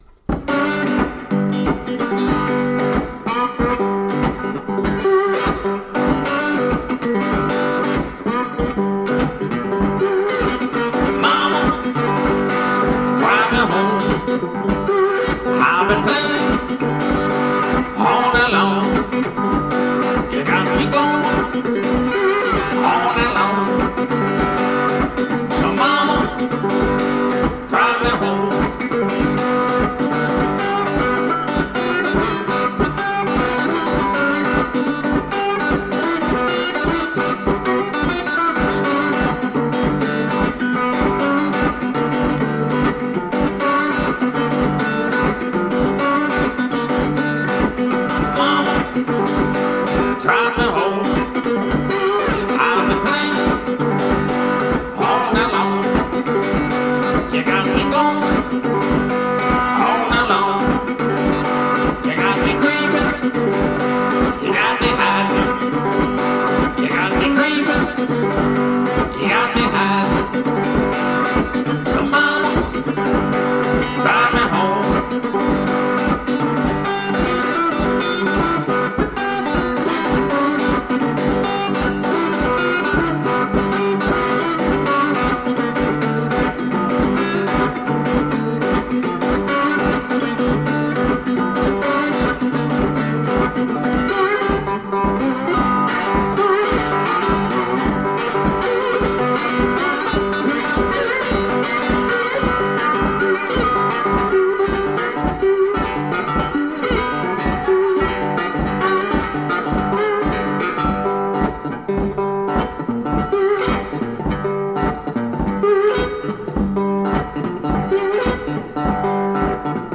Authentic 1920's solo blues guitar .
Tagged as: Jazz, Blues, Vocal, Electric Guitar